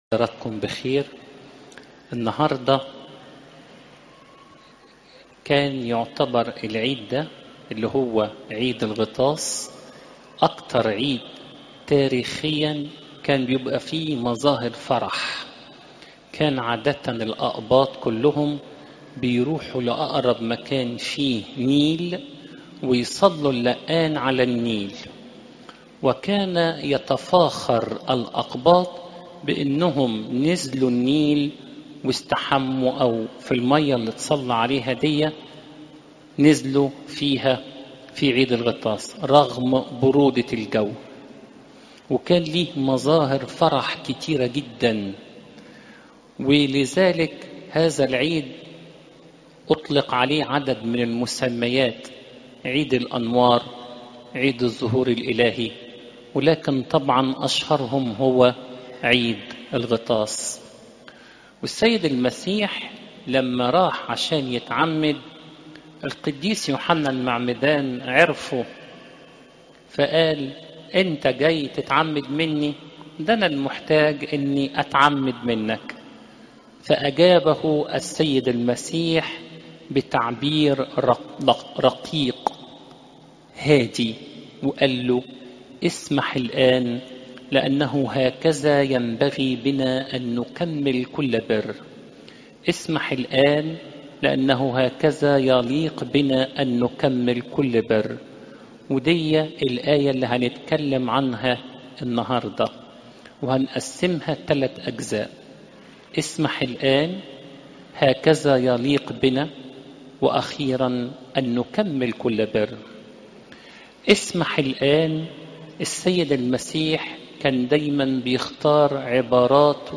عظات المناسبات عيد الغطاس (يو 1 : 18 - 34)